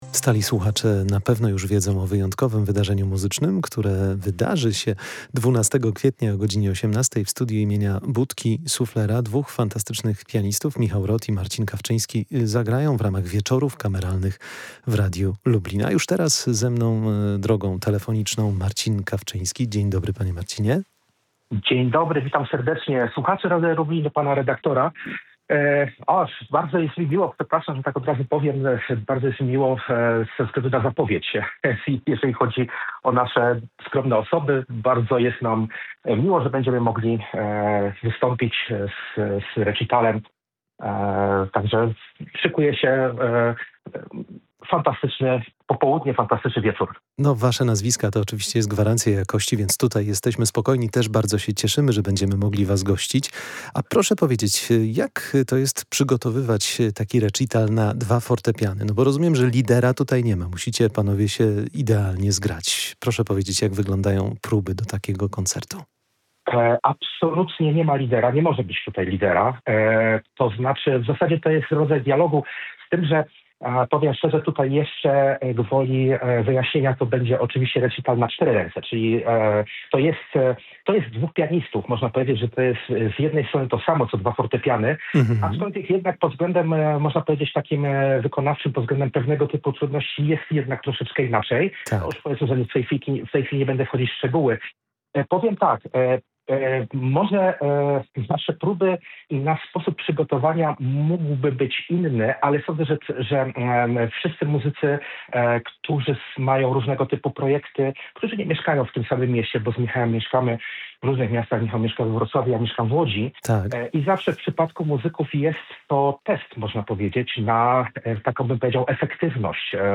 Wieczory Kameralne w Radiu Lublin – Koncert Fortepianowy &#8211
Radio Lublin serdecznie zaprasza na niezapomniane spotkanie z muzyką klasyczną w najlepszym wydaniu.
Już wkrótce nasze studio wypełni się dźwiękami fortepianu, a to wszystko w ramach cyklu „Wieczory kameralne”.